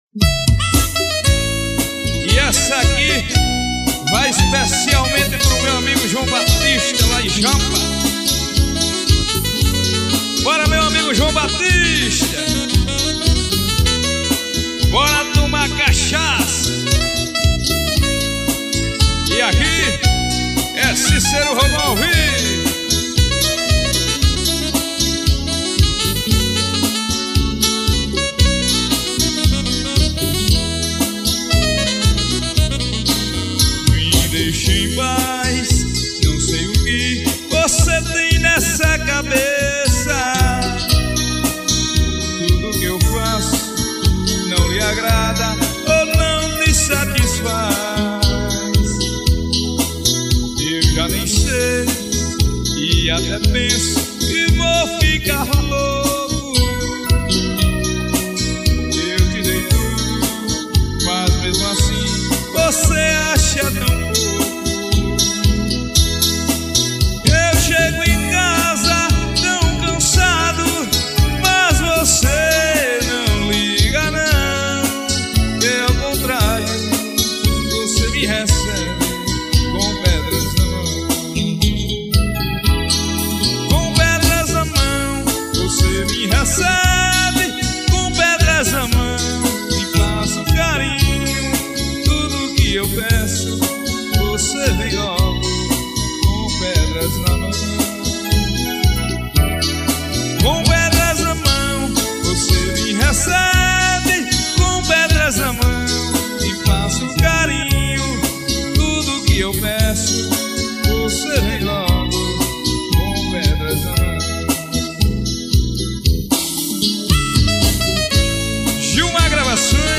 AO VIVO EM OROBÓ.